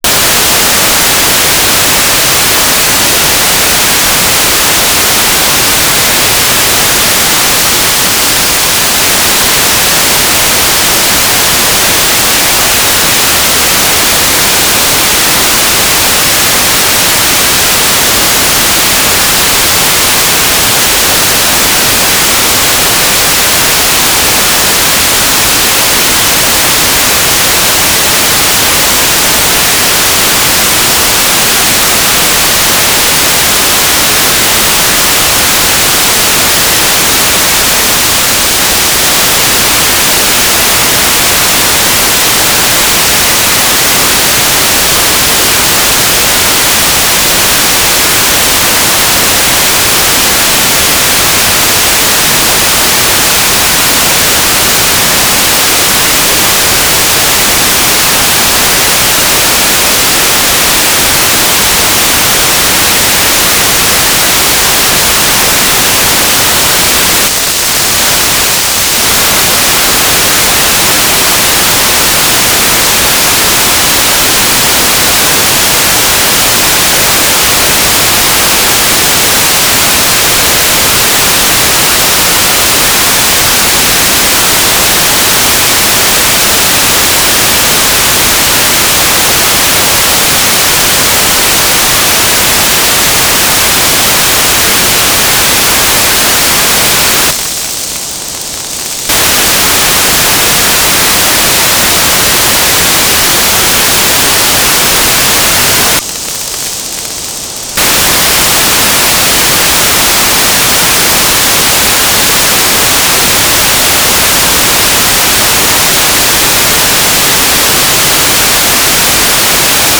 "transmitter_description": "Main telemetry transmitter",
"transmitter_mode": "GMSK USP",